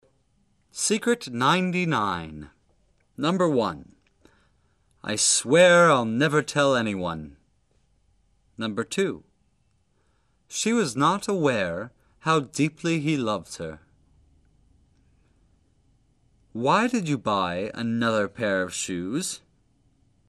在李阳疯狂英语中叫做“咧嘴集中卷舌”音。